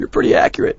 gutterball-3/Gutterball 3/Commentators/Bill/b_prettyaccurate.wav at 30d6695f0c05e0159e645caab8c4e19b00ced065
b_prettyaccurate.wav